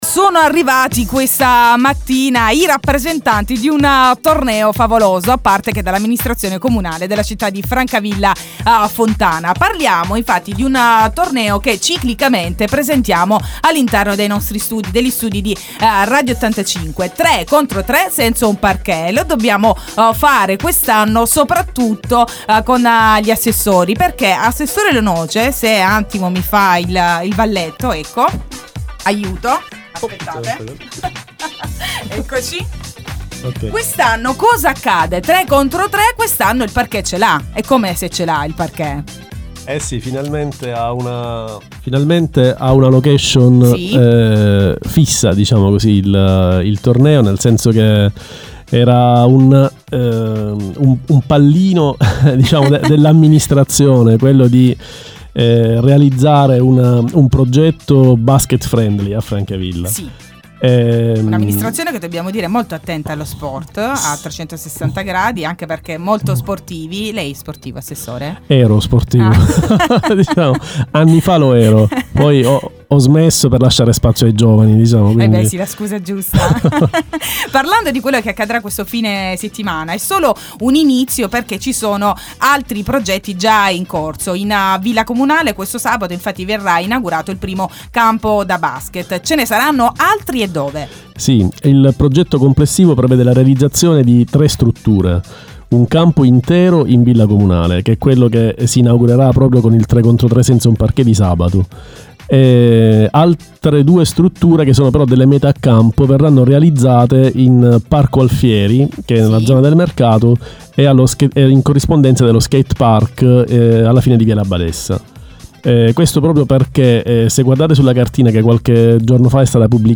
IN studio il sindaco Antonello Denuzzo, gli assessori Nicola Lonkice e MAria Angelotti